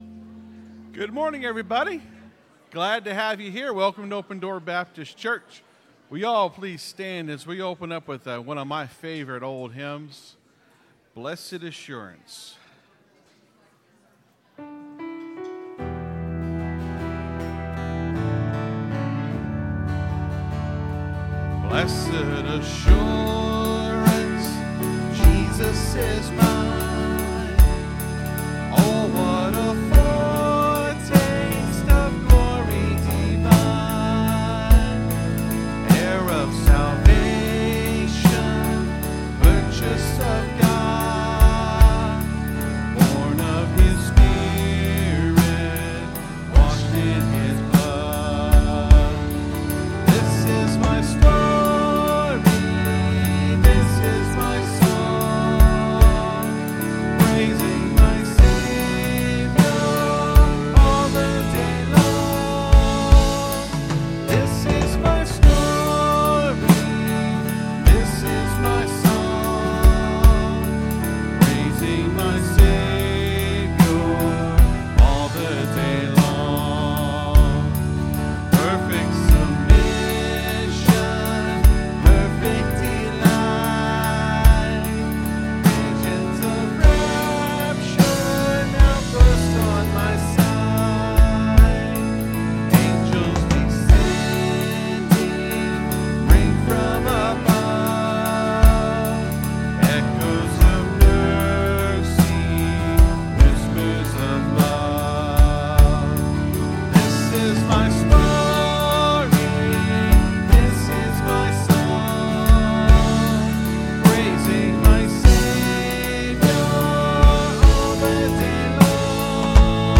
(Sermon starts at 26:25 in the recording).